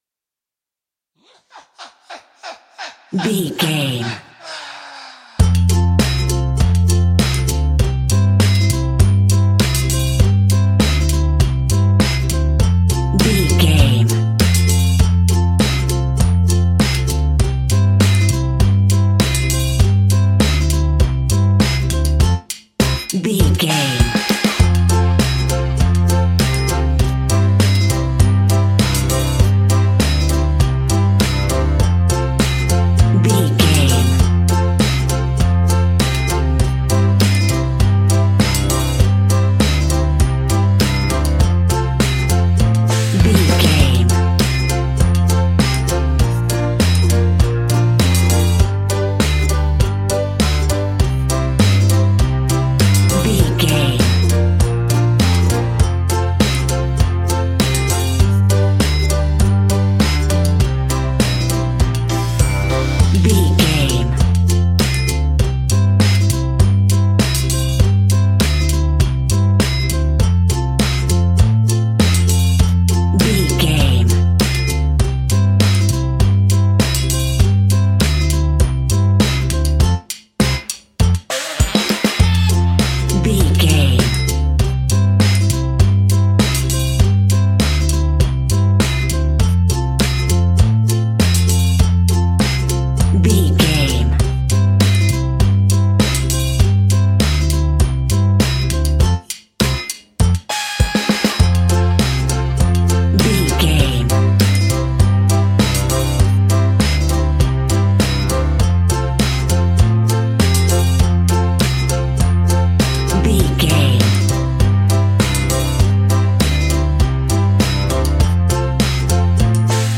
In-crescendo
Aeolian/Minor
ominous
haunting
eerie
horror music
Horror Pads
horror piano
Horror Synths